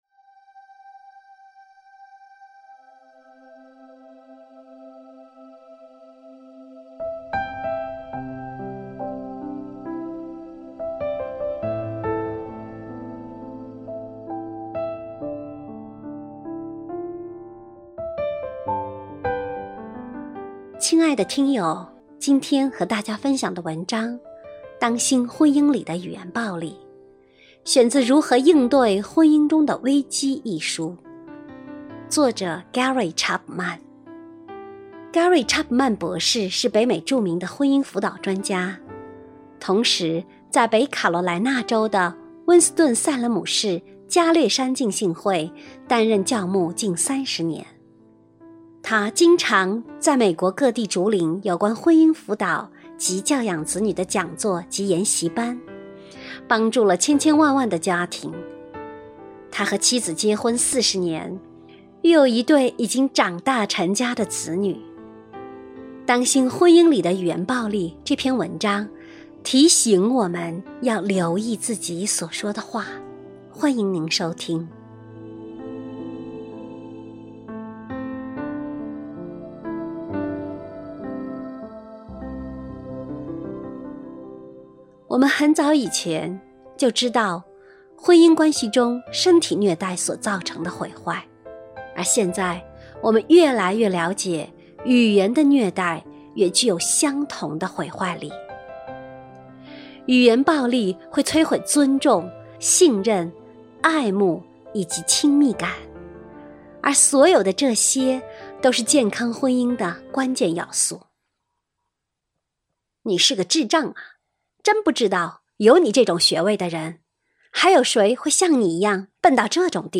首页 > 有声书 > 婚姻家庭 > 单篇集锦 | 婚姻家庭 | 有声书 > 当心婚姻里的语言暴力